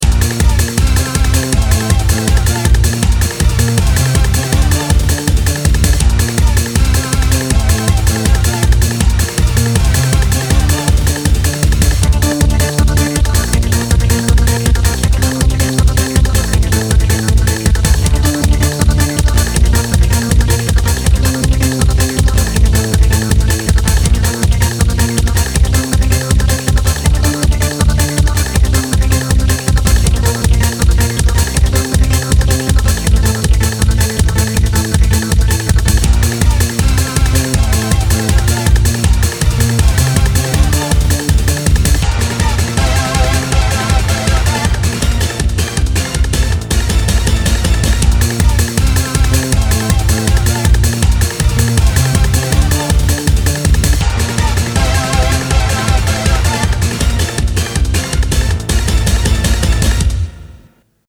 今回はサンプル素材をもとに音楽をつくっていきます。
このような、作業をたどってできた曲がこれです。